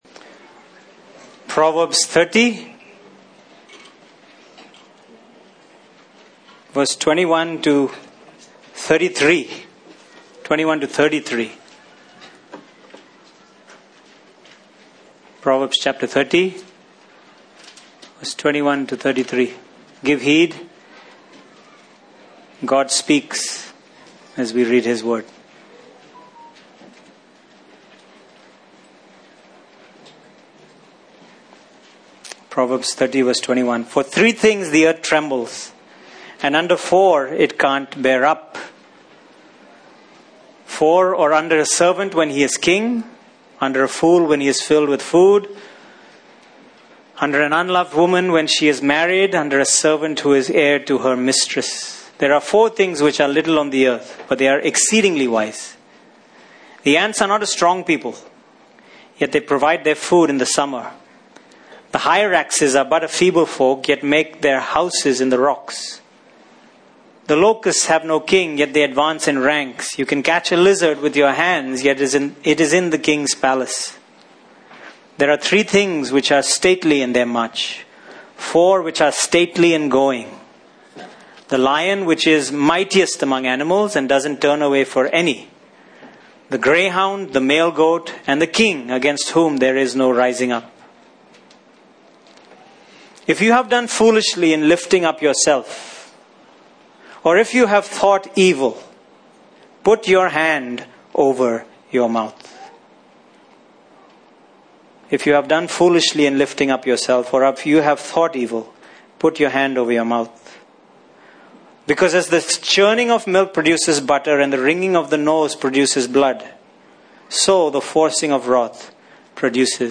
Passage: Proverbs 30:21-22 Service Type: Sunday Morning